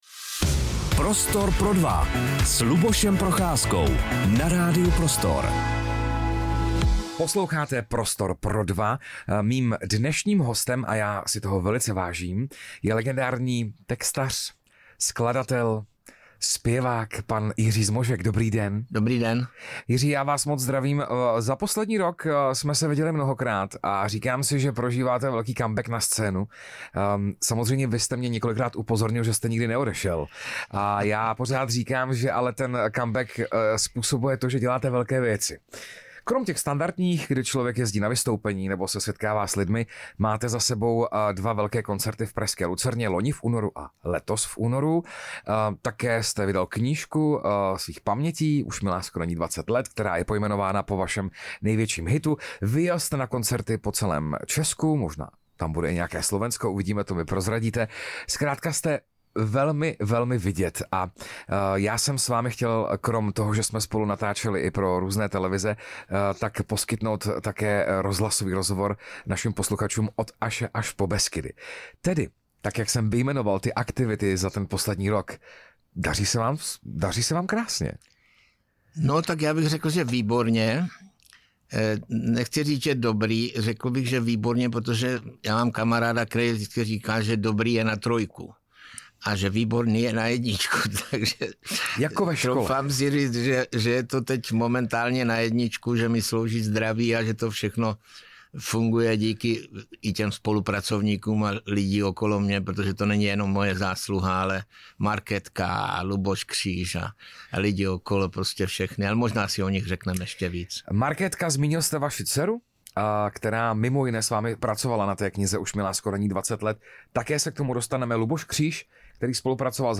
Dalším hostem Prostoru pro dva byl legendární muzikant a skladatel Jiří Zmožek. V rozhovoru mimo jiné zavzpomínal na své klíčové spolupracovníky, např. Karla Gotta či Daru Rolins. Prozradil také, že chystá další velký koncert v pražské Lucerně, před kterým bude vystupovat na mnoha místech Česka a Slovenska.